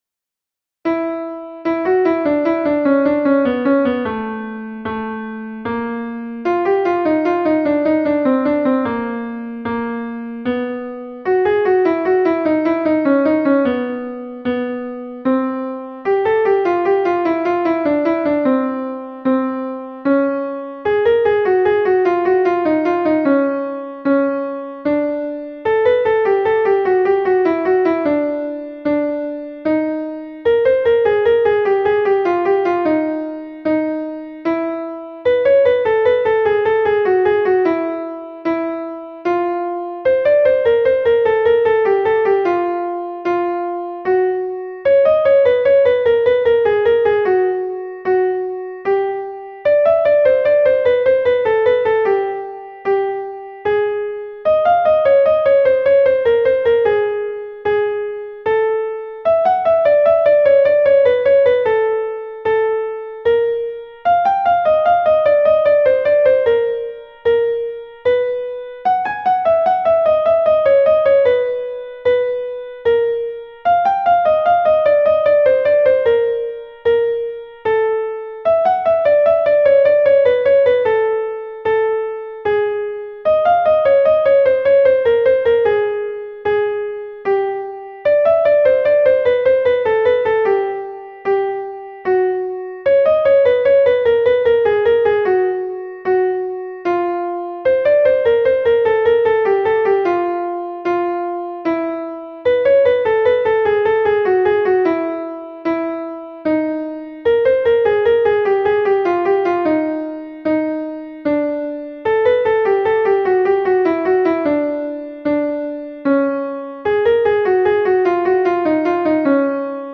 Es la capacidad de ejecutar con la voz muy rápidamente una secuencia de notas que por lo general suben o bajan por intervalos pequeños, precisando mucha agilidad y amplitud vocal.
Vocalización para Voces graves
Ejercitar-la-Coloratura-Voz-grave.mp3